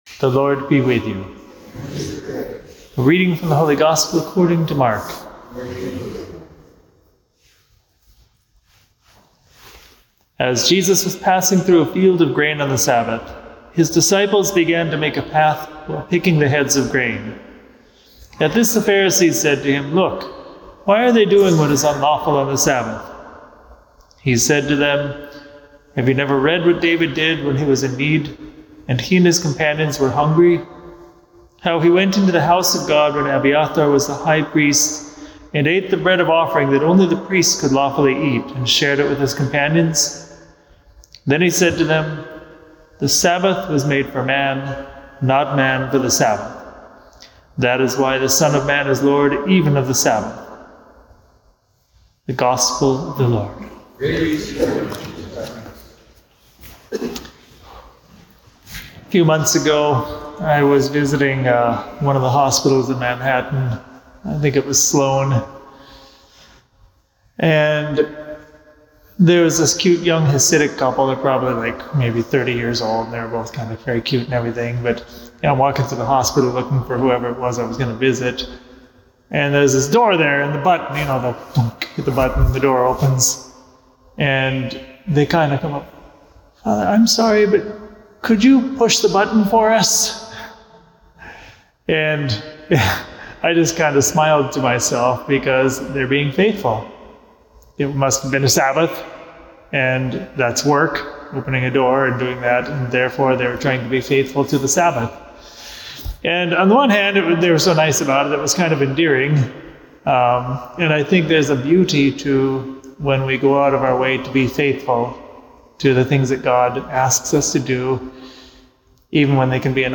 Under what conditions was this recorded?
at Resurrection Parish